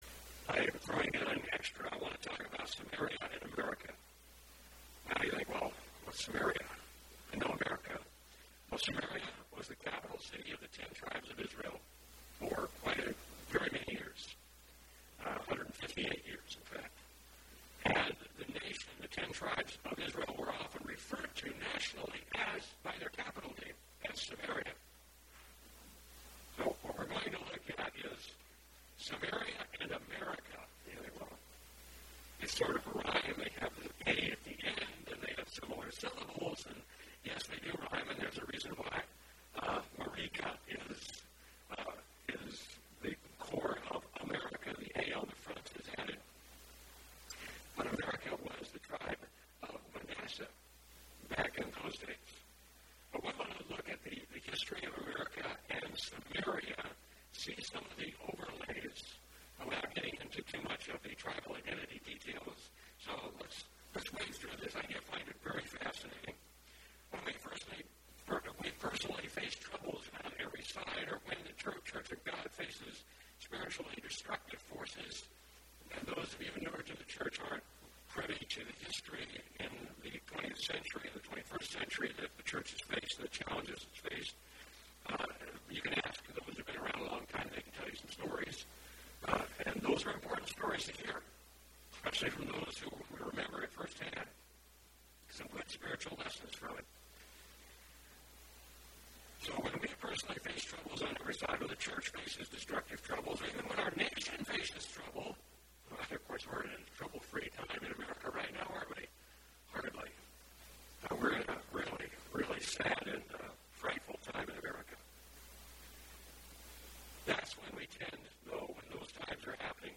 Given in Sioux Falls, SD Watertown, SD